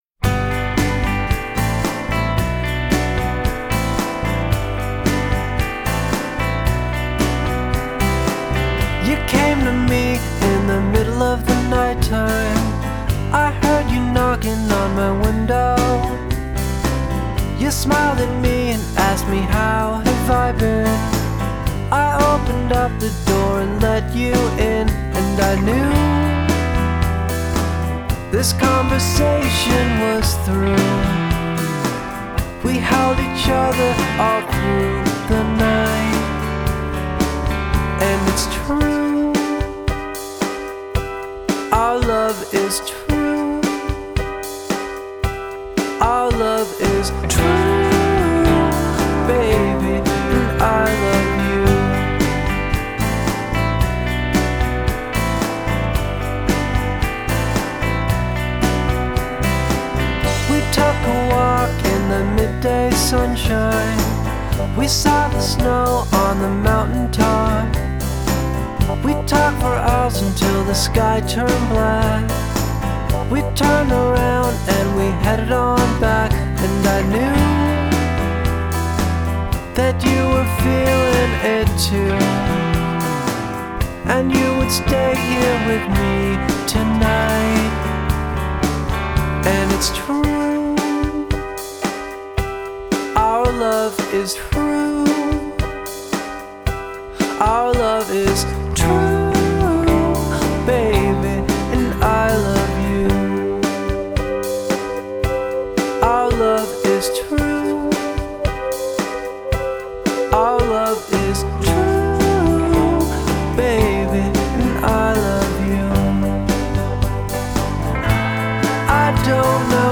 are delightful low key pop excursions.